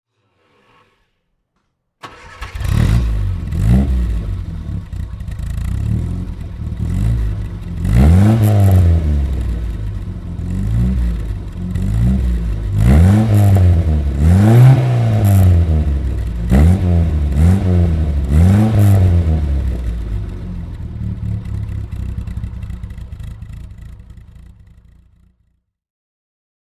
MG Midget Mk II (1964) - Starten und Leerlauf
MG_Midget_1964.mp3